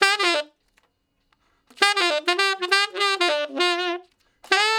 066 Ten Sax Straight (D) 02.wav